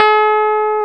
Index of /90_sSampleCDs/Roland - Rhythm Section/KEY_Pop Pianos 2/KEY_MKS20 P3+EP1